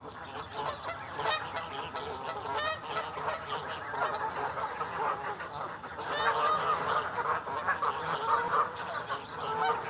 Pond_Geese.mp3